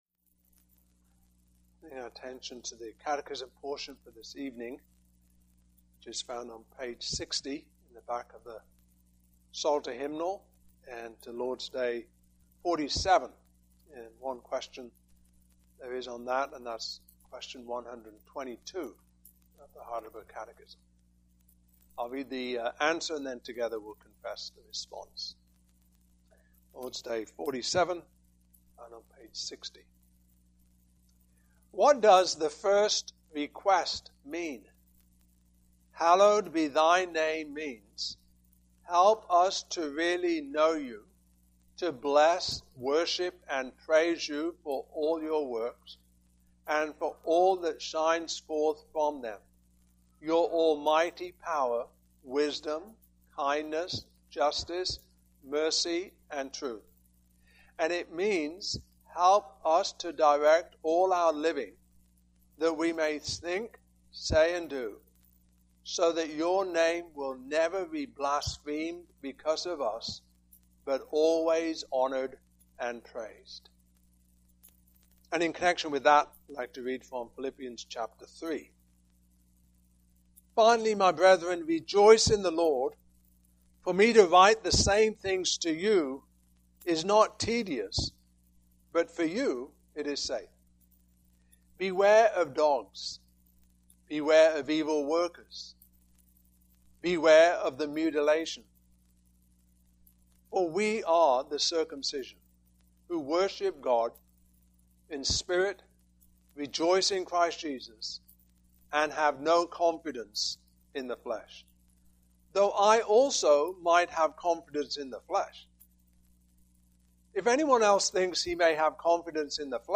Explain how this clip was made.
Heidelberg Catechism Series 2021 Passage: Philippians 3:1-21 Service Type: Evening Service Topics